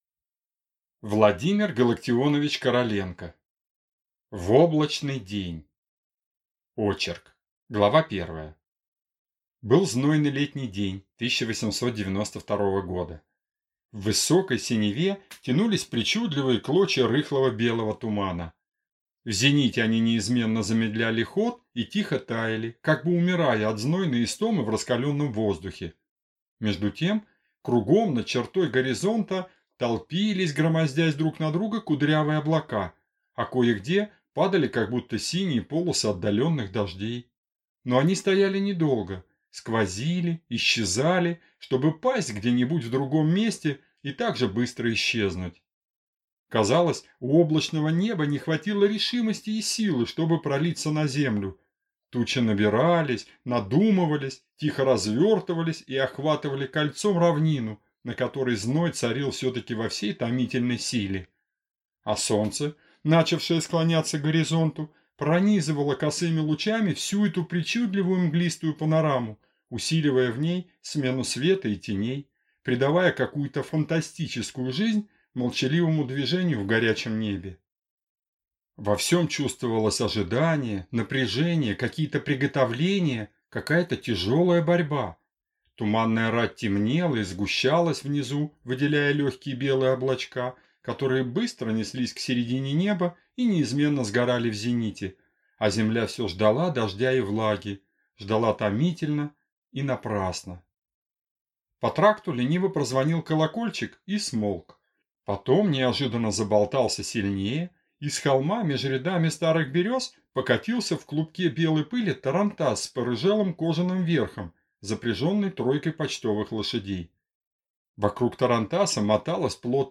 Аудиокнига В облачный день | Библиотека аудиокниг